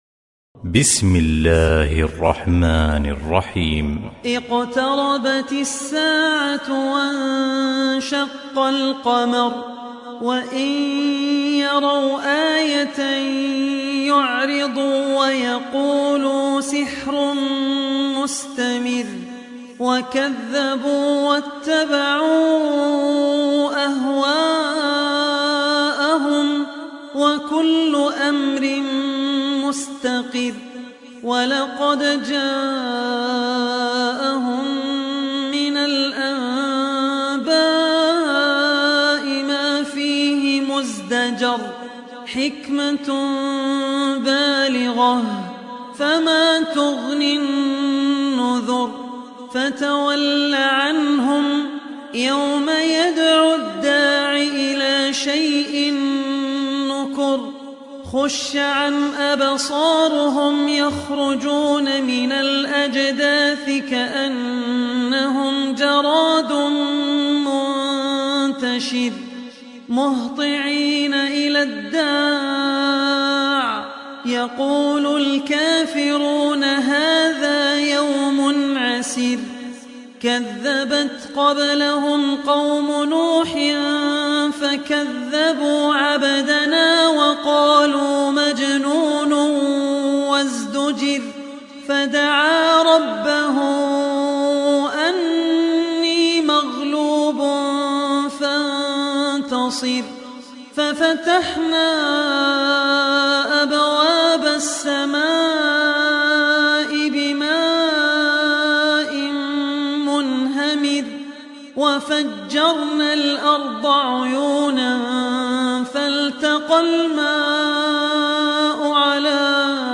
Kamer Suresi İndir mp3 Abdul Rahman Al Ossi Riwayat Hafs an Asim, Kurani indirin ve mp3 tam doğrudan bağlantılar dinle